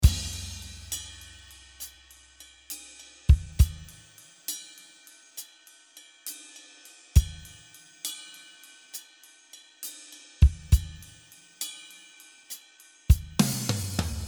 25 loops playing at 101 bpm in 6/8 rhythm.
13 loops that are playing tom fills , 3 loops with x-stick , 5 loops with snare and 4 loops with ride cymbals.
It is a basic 6/8 rhythm.